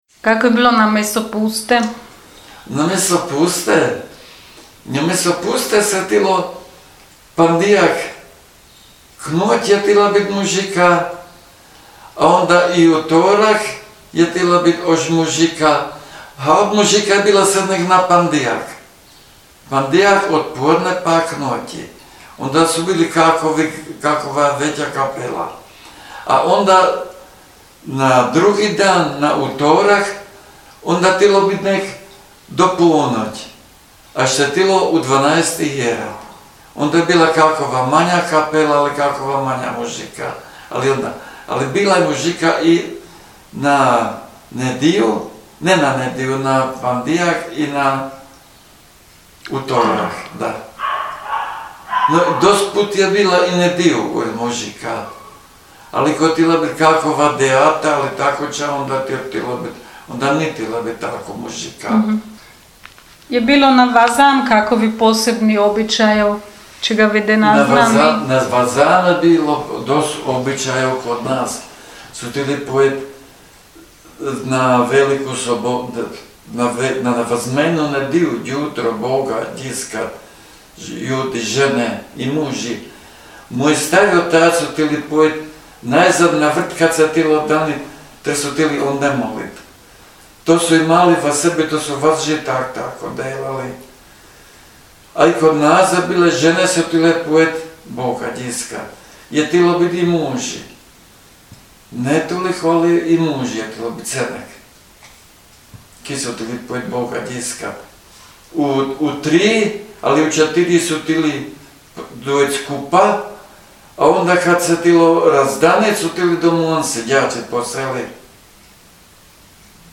jezik naš, jezik naš gh dijalekti
Frakanava – Govor